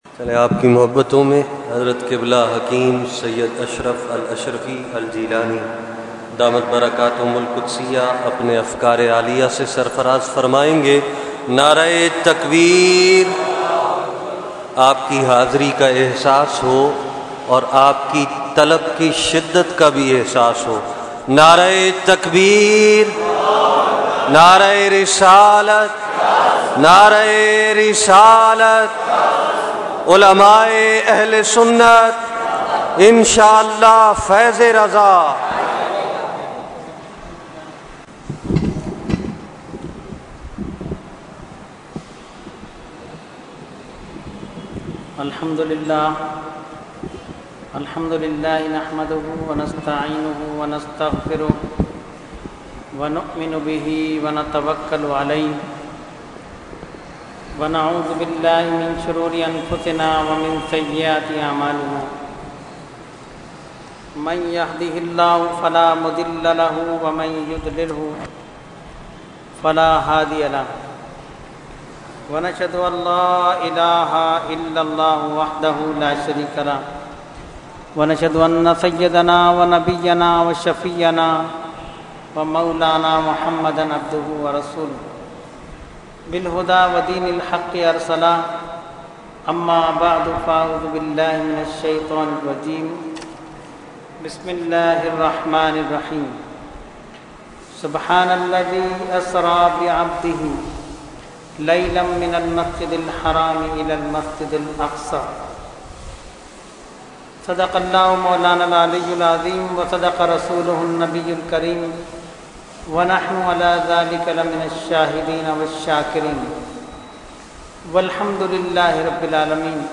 Category : Speech | Language : UrduEvent : Dars Quran Farooqi Masjid 8 June 2012